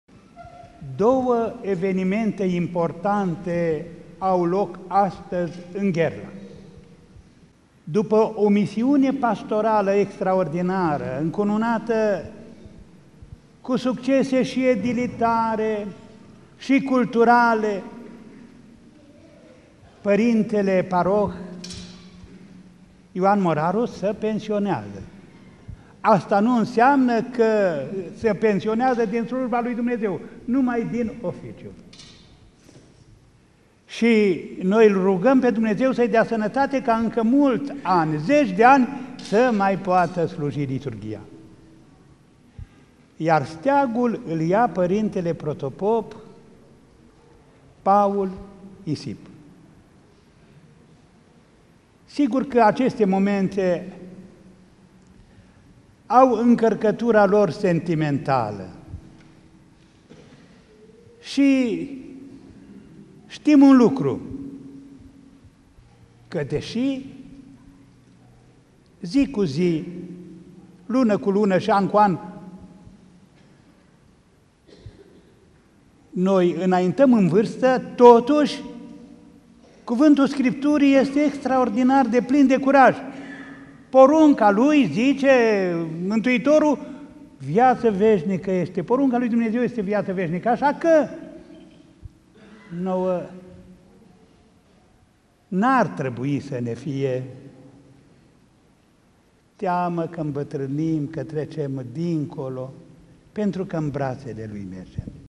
Fragment-IPS-Andrei-pensionare-si-instalare.mp3